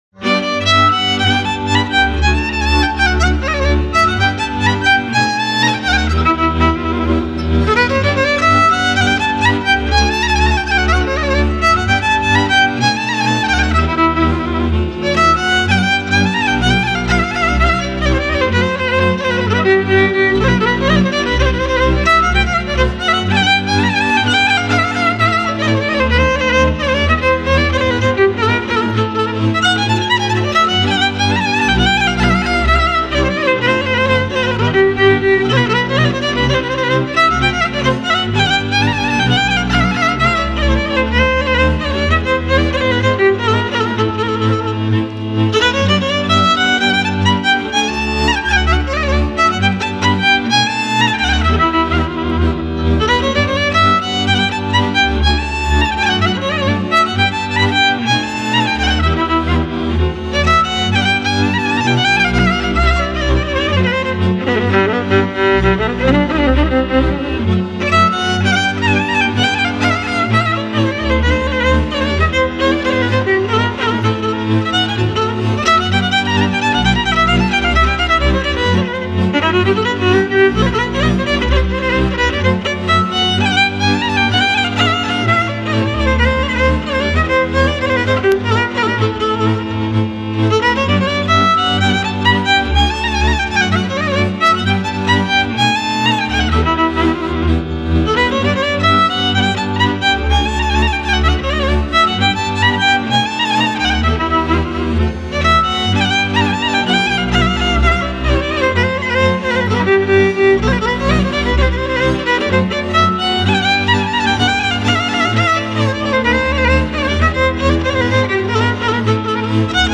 - realizează o bună omogenitate timbrală datorată celor trei instrumente din categoria “cu  coarde şi arcuş”
contrabasul  – frecvenţele grave
braciul cu trei coarde – frecvenţele medii
vioara – frecvenţele înalte